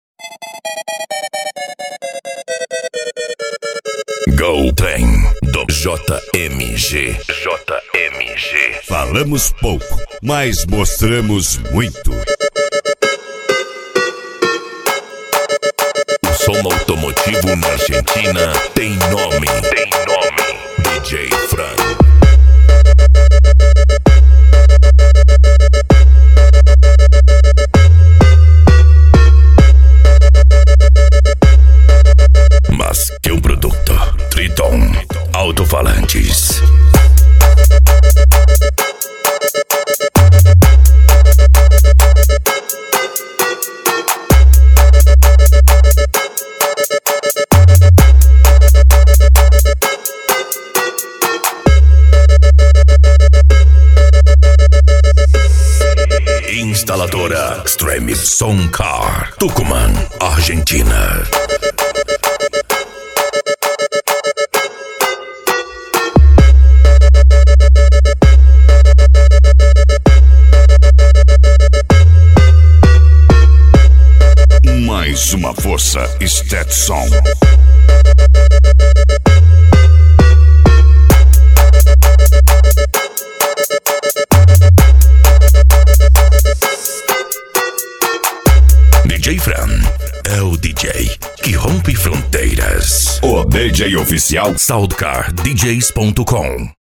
Bass
Cumbia
PANCADÃO
Remix